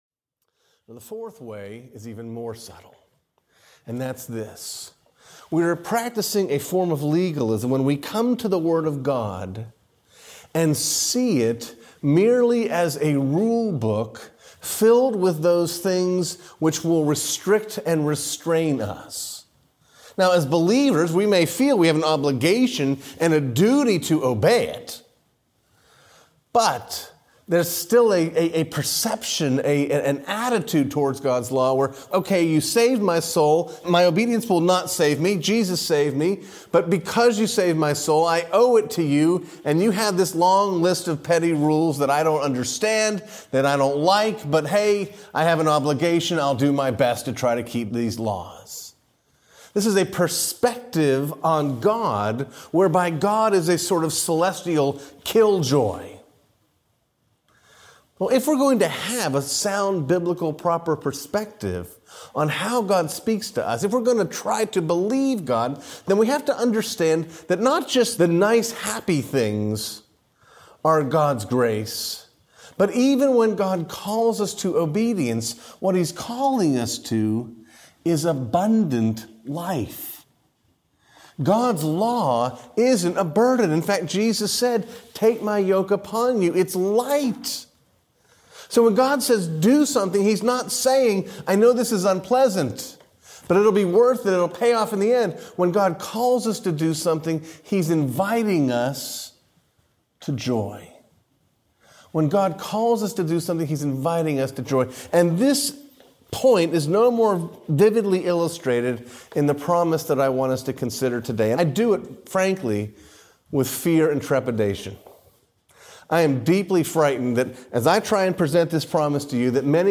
Believing God Audiobook